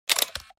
دانلود صدای دوربین 1 از ساعد نیوز با لینک مستقیم و کیفیت بالا
جلوه های صوتی